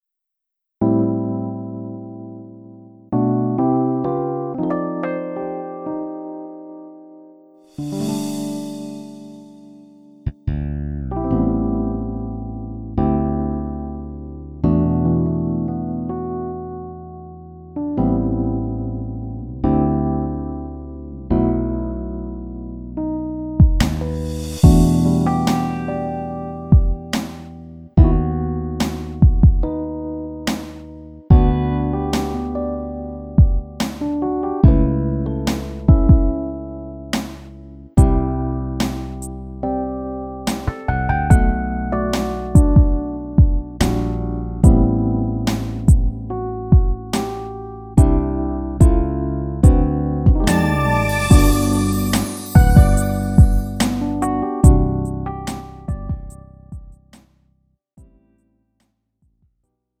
음정 원키 3:39
장르 가요 구분 Lite MR